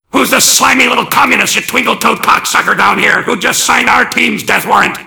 mvm_bomb_alerts05.mp3